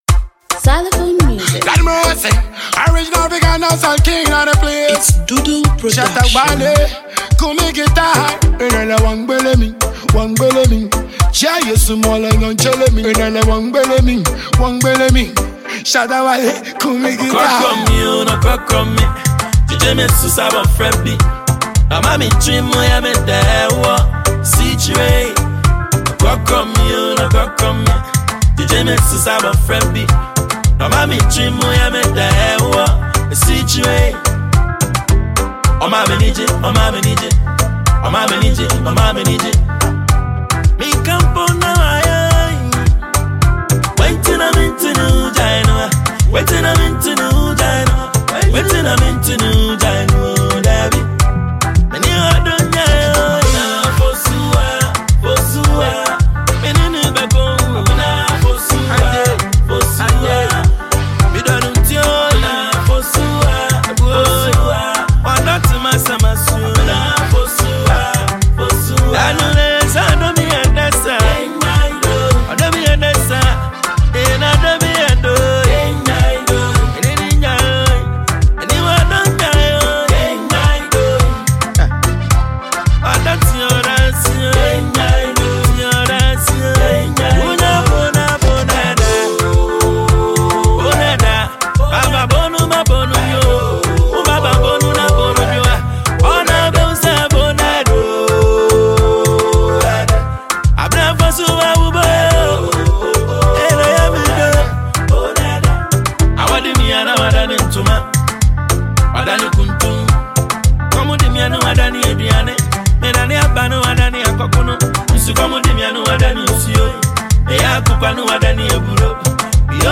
Ghana MusicMusic
highlife singer
multiple award-winning dancehall musician